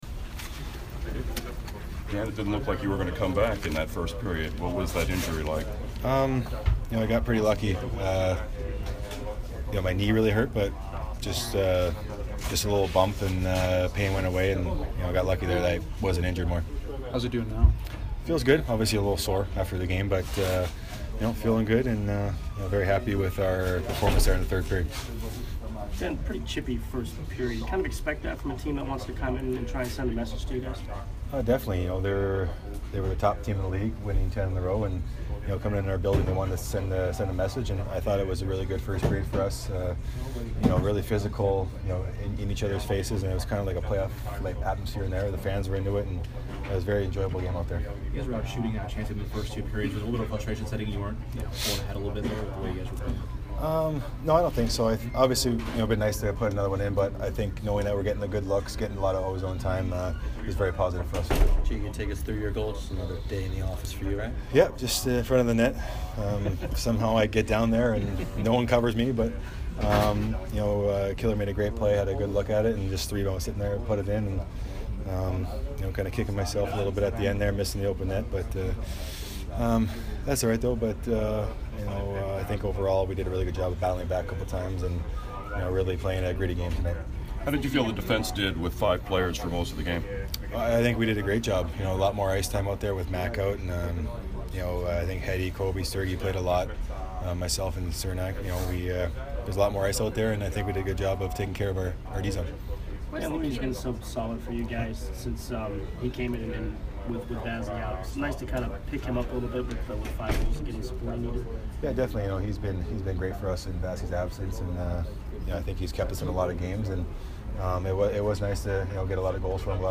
Dan Girardi post-game 11/29